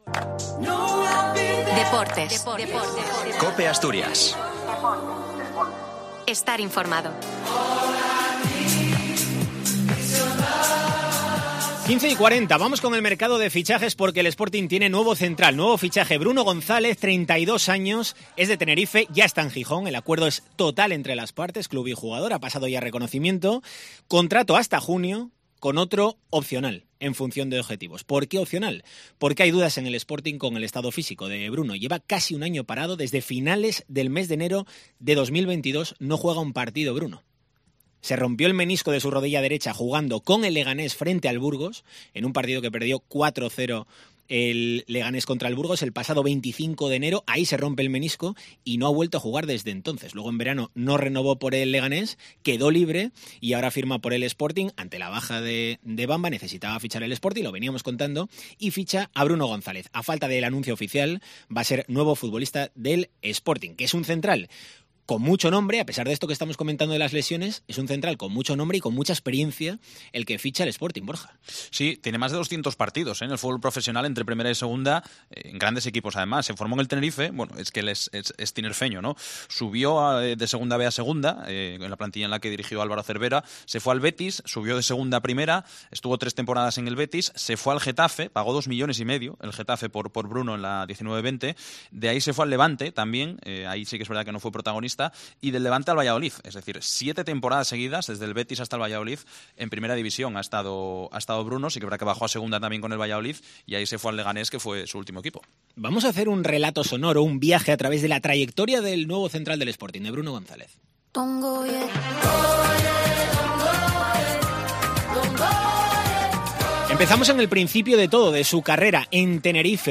Conoce al nuevo fichaje rojiblanco a través del viaje sonoro por su carrera. Distintos periodistas de COPE analizan la trayectoria del zaguero en sus diferentes equipos.